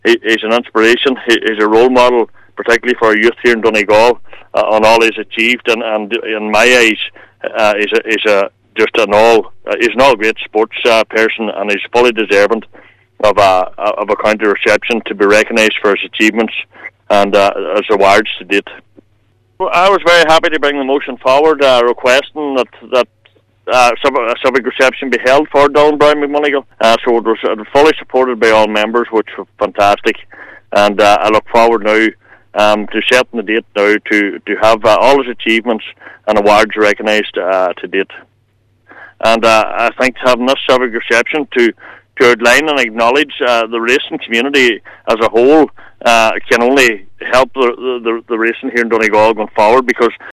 Cllr Kelly says Browne McMonagle says he represents Donegal on the national and international stage: